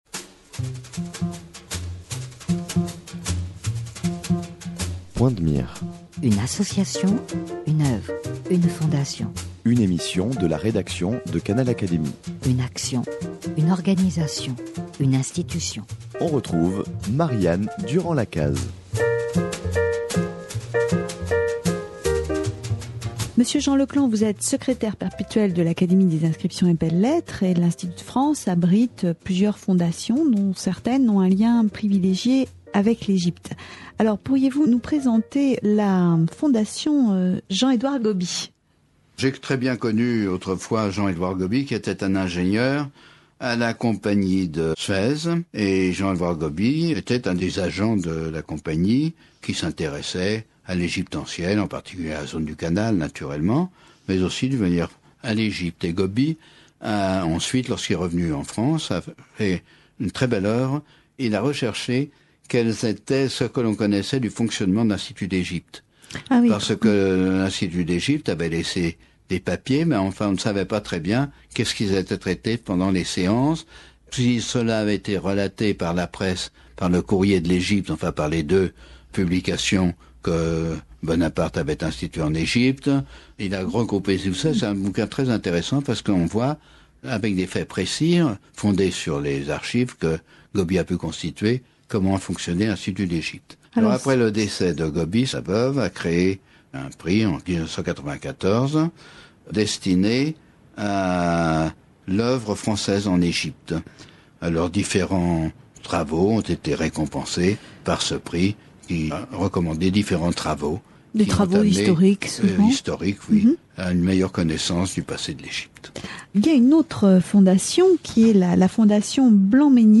Entretien avec Jean Leclant, ancien secrétaire perpétuel de l’Académie des inscriptions et belles-lettres